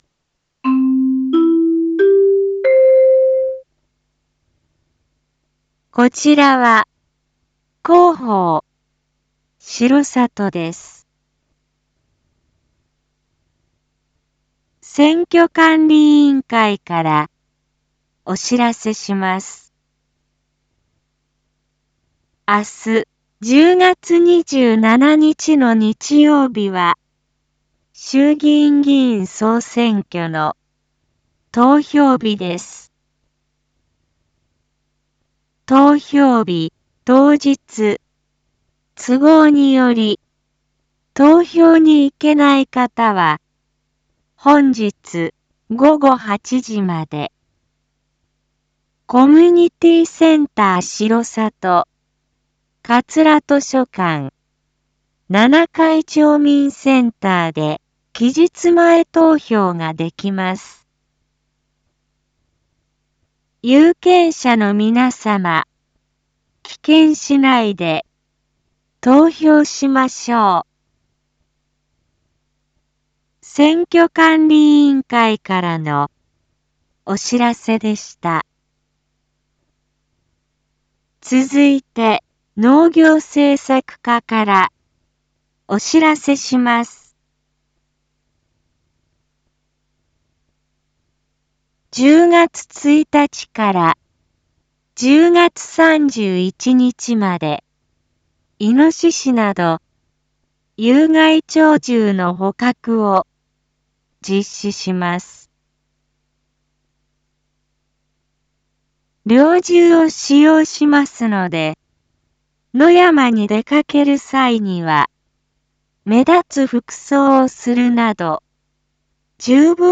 Back Home 一般放送情報 音声放送 再生 一般放送情報 登録日時：2024-10-26 19:02:31 タイトル：⑮有害鳥獣捕獲について インフォメーション：こちらは、広報しろさとです。